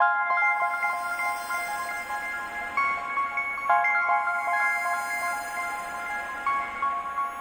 MB - Loop 6 - 65BPM.wav